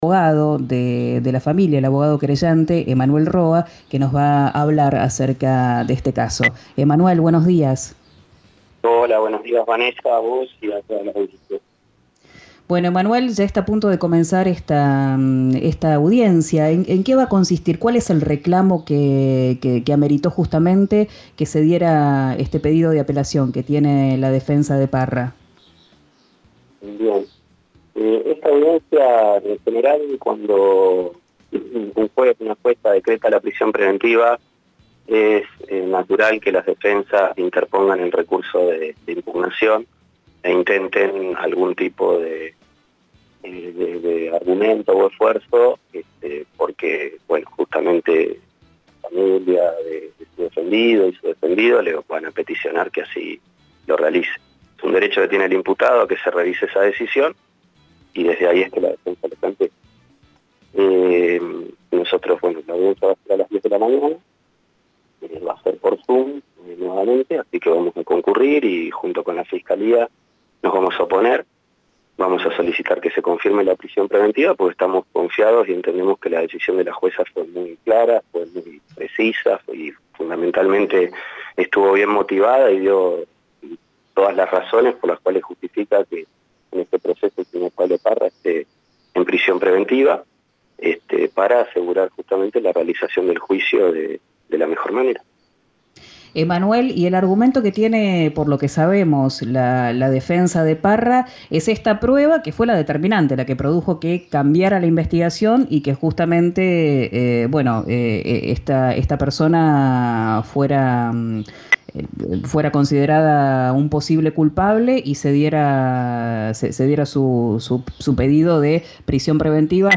Escuchá al abogado querellante